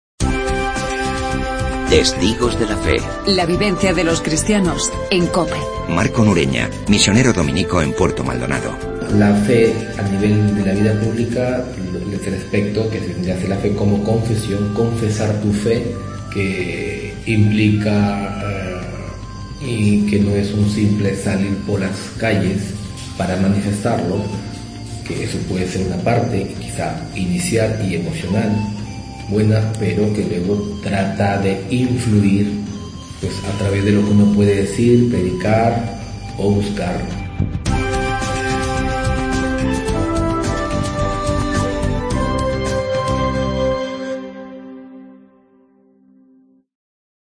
AUDIO: Escuchamos el testimonio